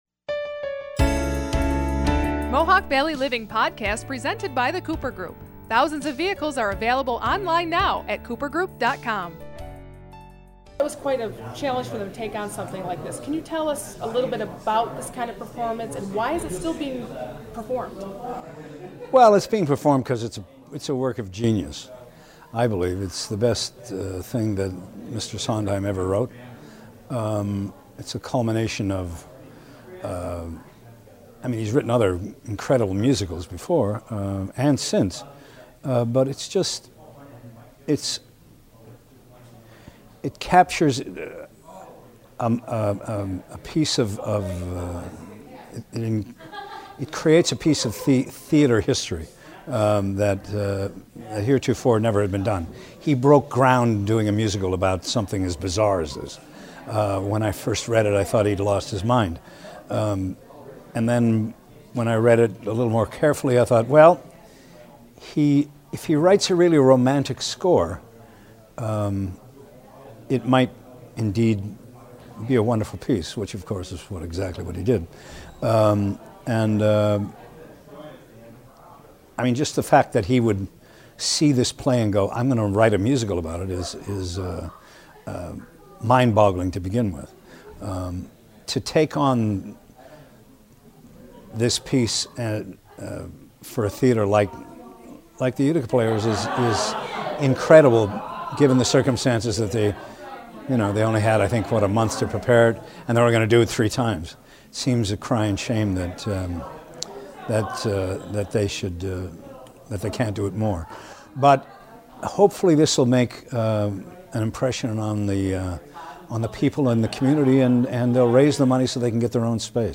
3. Extended Len Cariou interview.